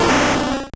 Cri d'Ectoplasma dans Pokémon Or et Argent.